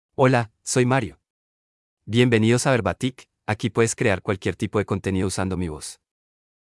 MaleSpanish (Paraguay)
MarioMale Spanish AI voice
Mario is a male AI voice for Spanish (Paraguay).
Voice sample
Mario delivers clear pronunciation with authentic Paraguay Spanish intonation, making your content sound professionally produced.